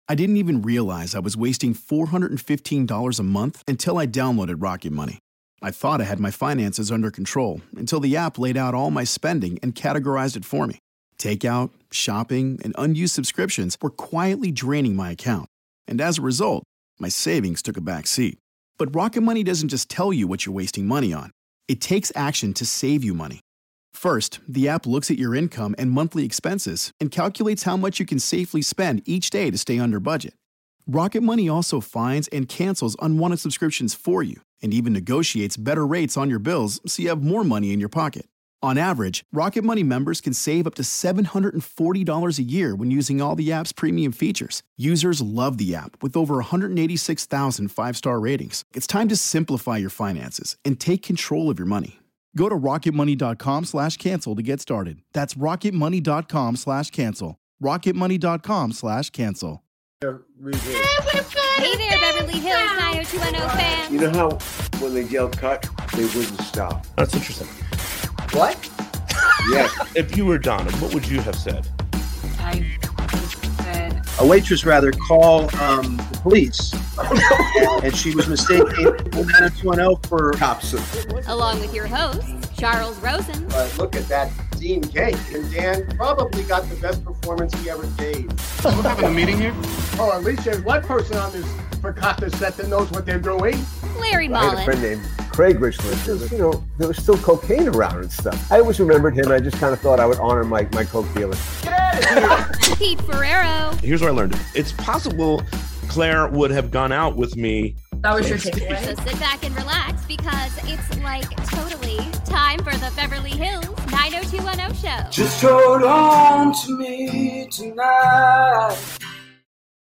Superfans chat about how Beverly Hills, 90210 impacted their lives for a Thanksgiving episode.